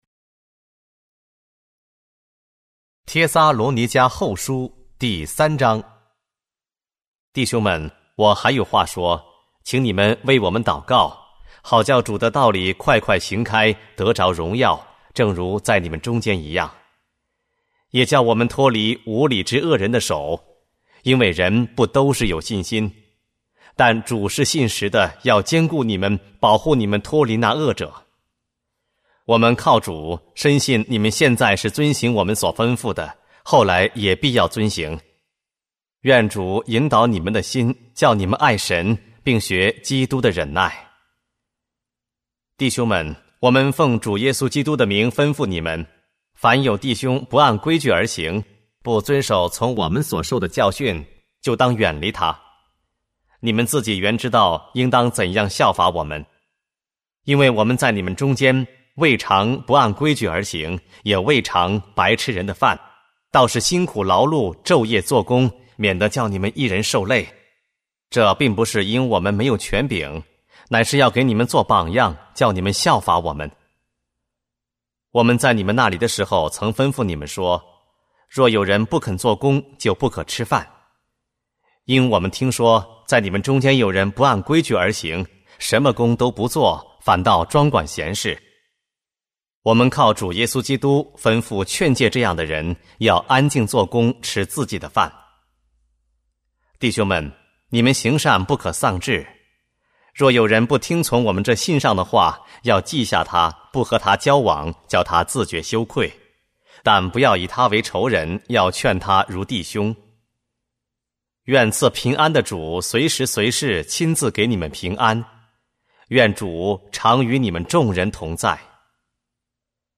和合本朗读：帖撒罗尼迦后书 – 恩典材料